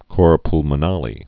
(kôr plmə-nälē, -nălē, pŭl-)